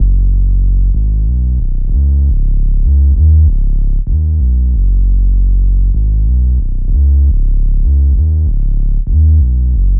FK096BASS1-L.wav